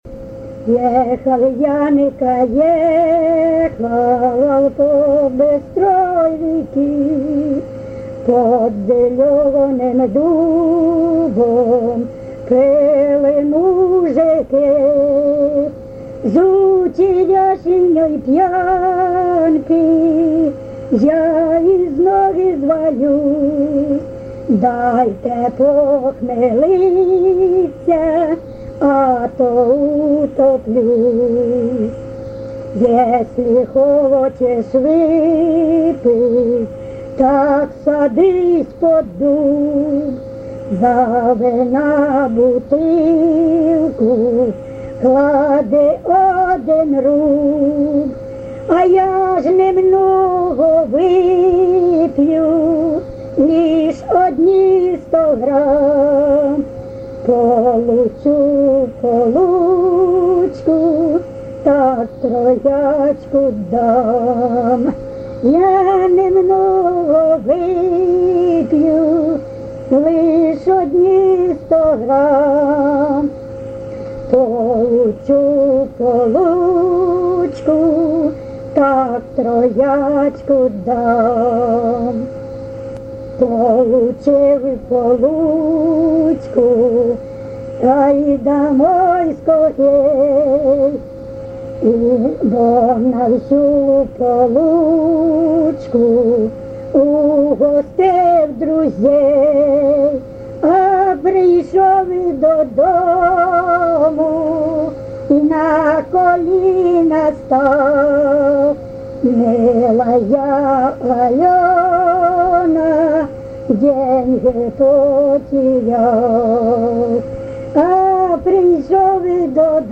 ЖанрПісні з особистого та родинного життя, Сучасні пісні та новотвори
Місце записус. Коржі, Роменський район, Сумська обл., Україна, Слобожанщина